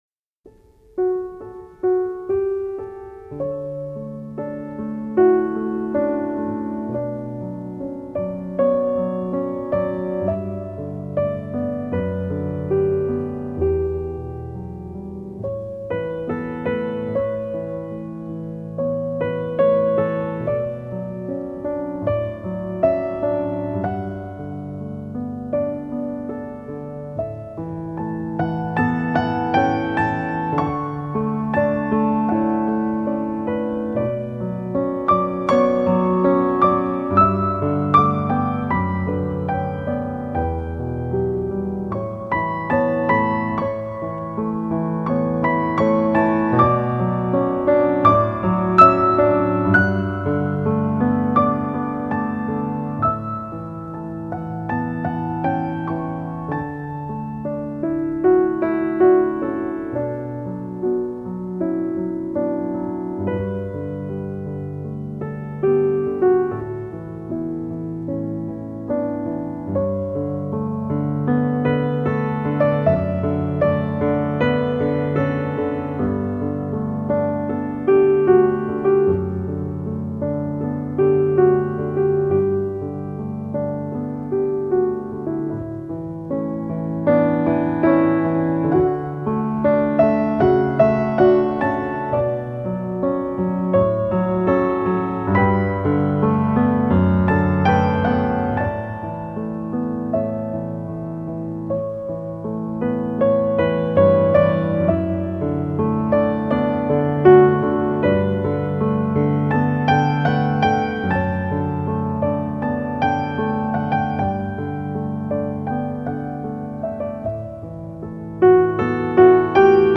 让心灵感受一片纯净与自然
绚丽复杂的旋律，也很少使用自然界的原声来强调主题，相反，那近